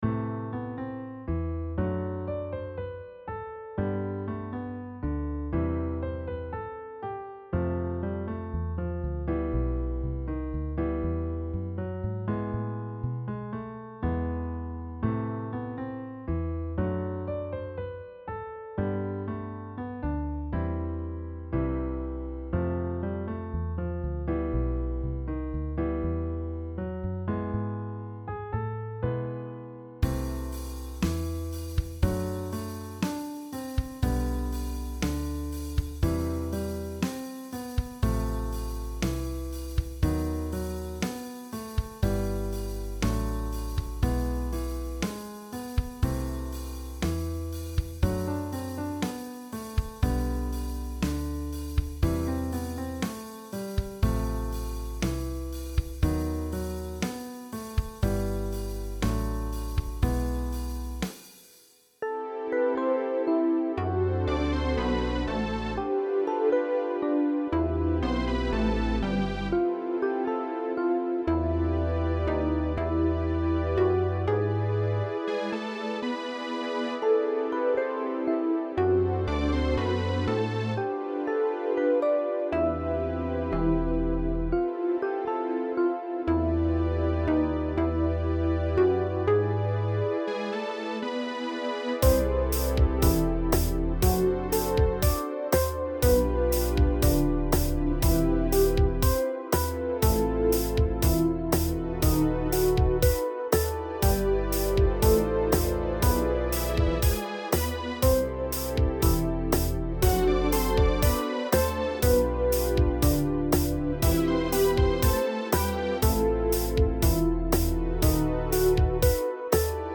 ProgRockBallad